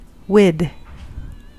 Uttal
Alternativa stavningar with (informella ord) wiv (informella ord) wif Uttal US Okänd accent: IPA : /wɪd/ Ordet hittades på dessa språk: engelska Ingen översättning hittades i den valda målspråket.